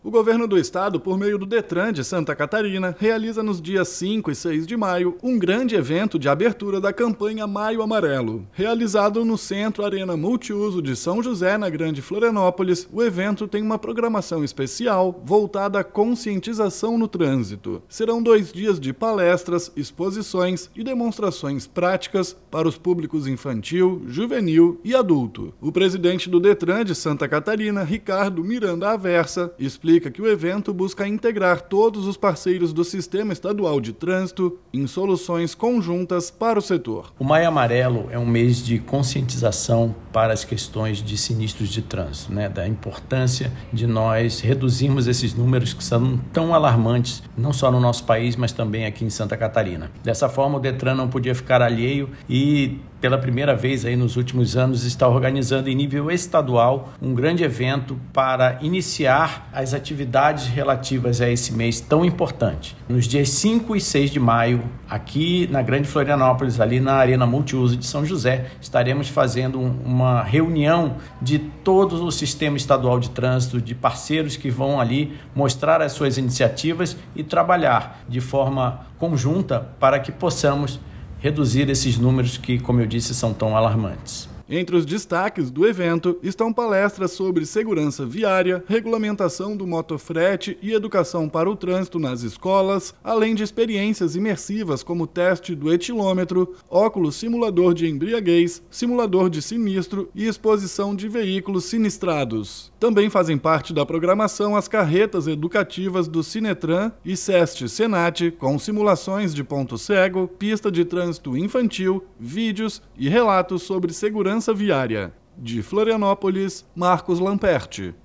O presidente do Detran/sc, Ricardo Miranda Aversa, explica que o evento busca integrar todos os parceiros do sistema estadual de trânsito em soluções conjuntas na área: